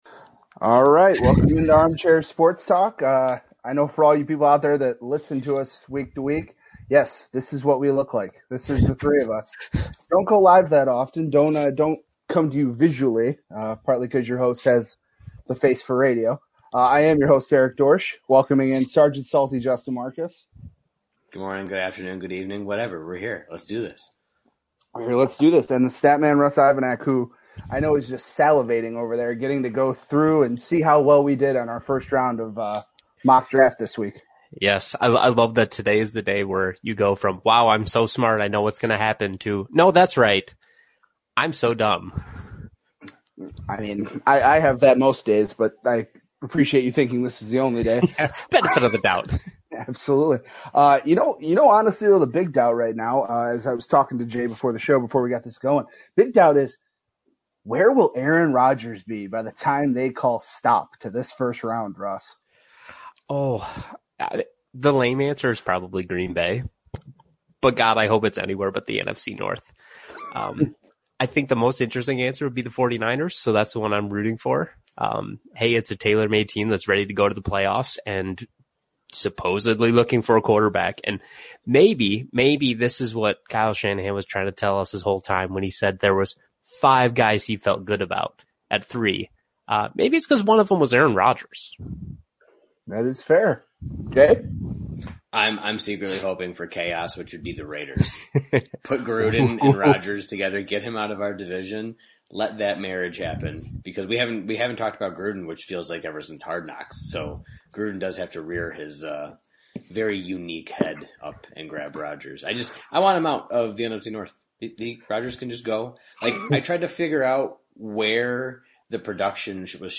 Guys go live to bring you the first round of the 2021 NFL Draft